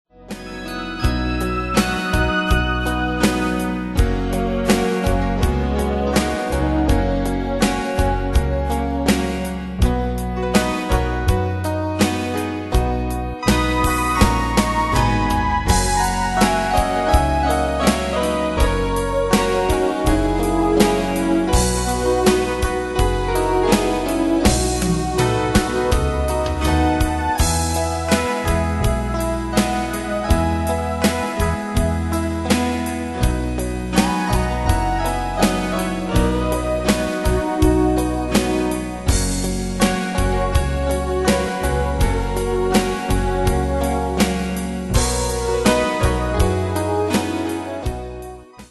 Style: Country Ane/Year: 1998 Tempo: 82 Durée/Time: 3.41
Danse/Dance: Ballade Cat Id.
Pro Backing Tracks